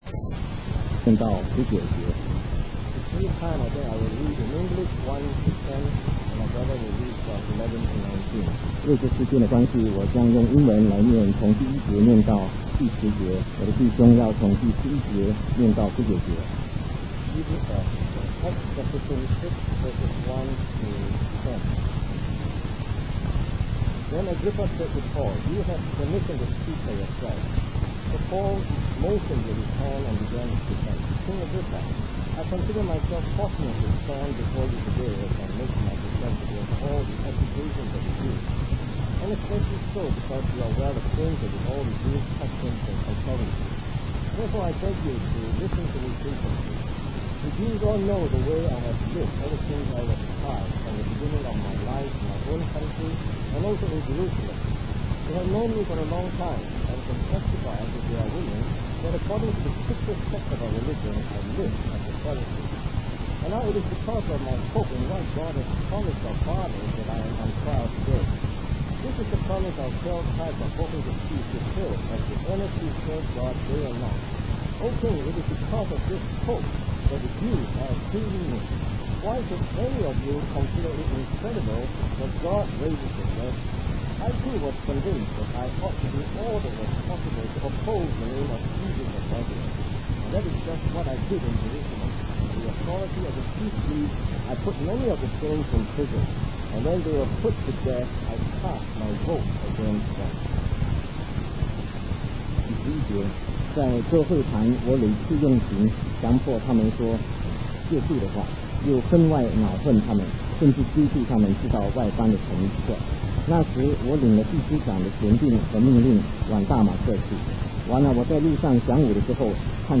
In this sermon, the preacher discusses the transformation of Saul, who later became known as Paul, and his encounter with Jesus on the road to Damascus.